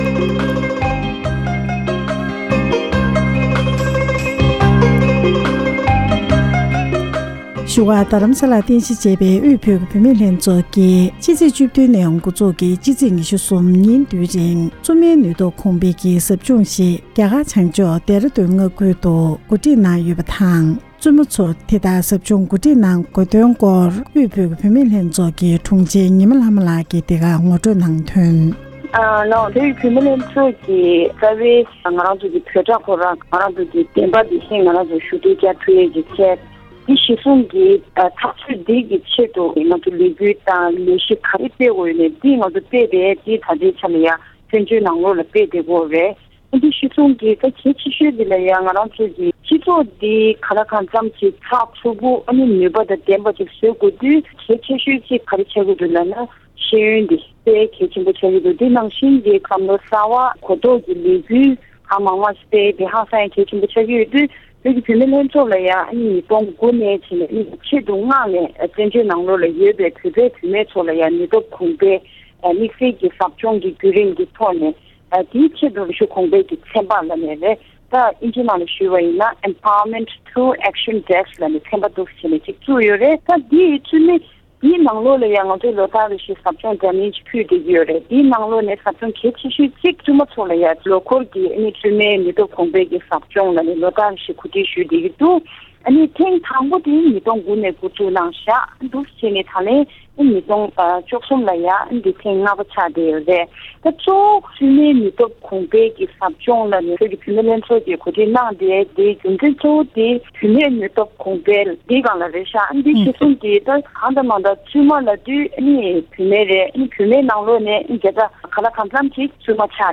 འབྲེལ་ཡོད་མི་སྣར་གནས་འདྲི་ཞུས་པ་ཞིག་ལ་གསན་རོགས་ཞུ༎